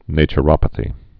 (nāchə-rŏpə-thē)